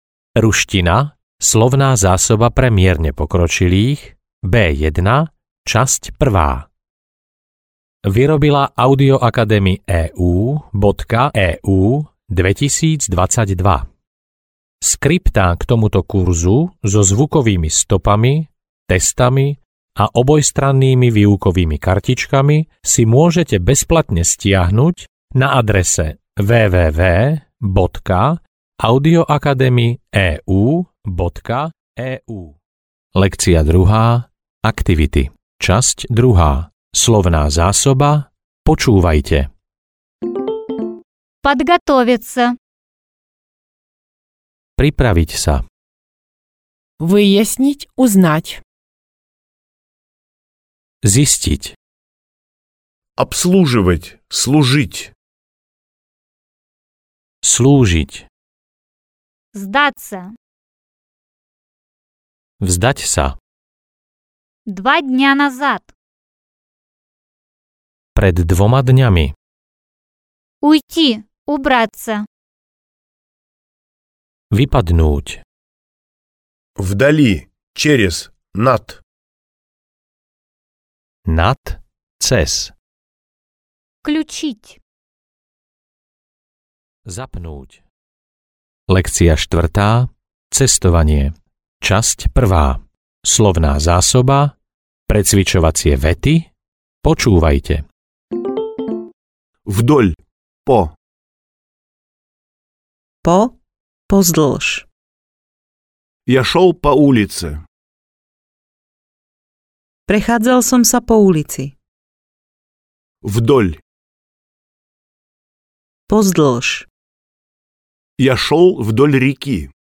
Ruština pre mierne pokročilých B1 - časť 1 audiokniha
Ukázka z knihy